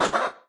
Media:RA_Dragon_Chicken_atk_clean_005.wav 攻击音效 atk 局内攻击音效
RA_Dragon_Chicken_atk_clean_005.wav